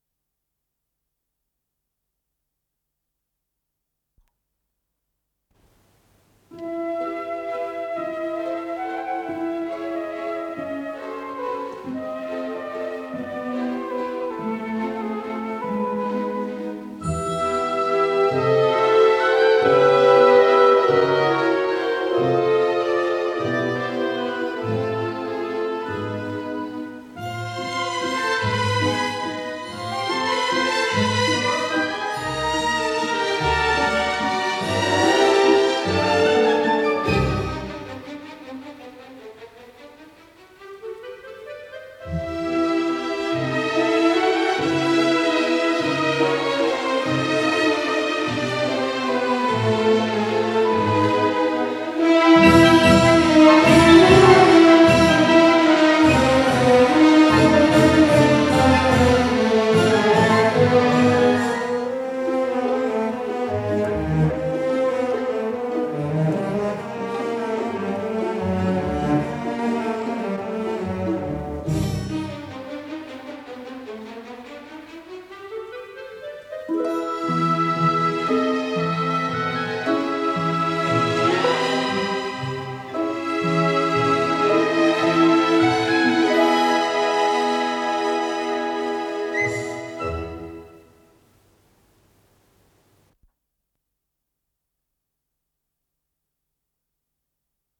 с профессиональной магнитной ленты
ВариантМоно